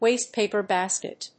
アクセントwástepàper bàsket